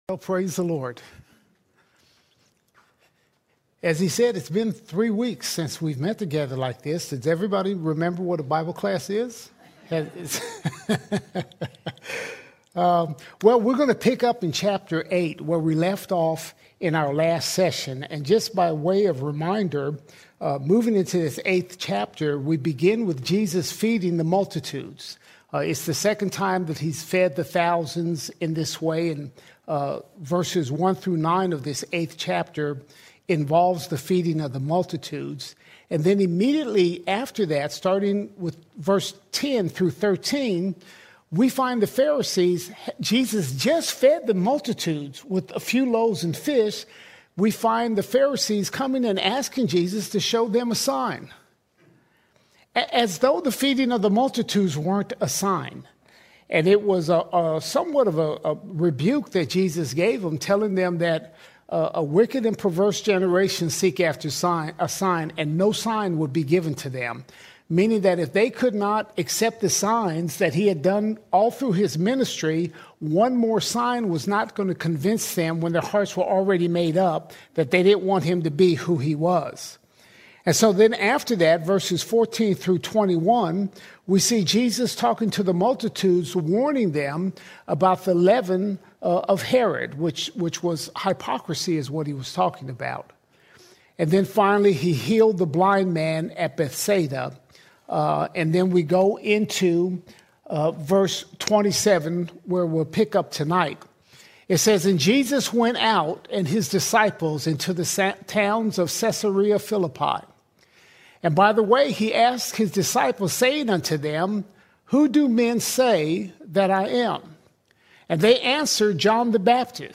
15 January 2026 Series: Gospel of Mark All Sermons Mark 8:27 - Mark 9:7 Mark 8:27 – Mark 9:7 Jesus reveals who He truly is -Messiah, Suffering Servant, and glorious Son!